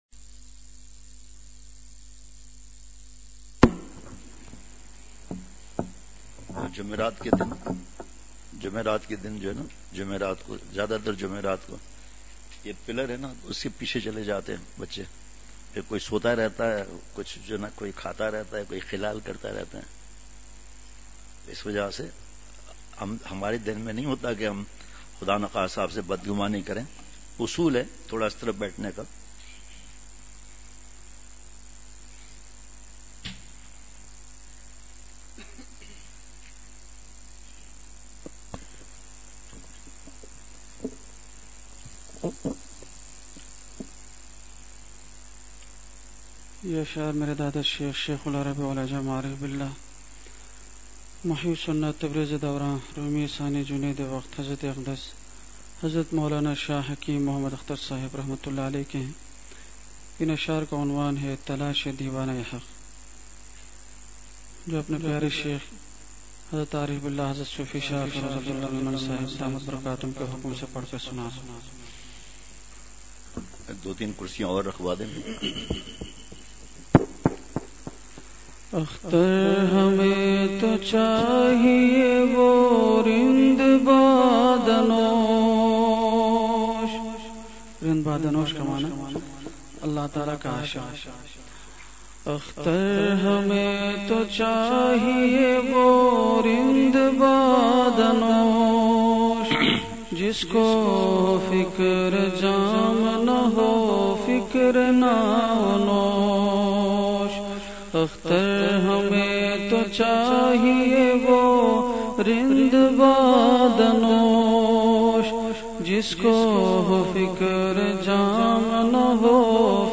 مدارس کے لئے لائیو بیان ۸ نومبر ۲۵ء:اسمارٹ فون کا غلط استعمال !
مقام:مسجدِ اختر نزدسندھ بلوچ سوسائٹی گلستانِ جوہر بلاک12کراچی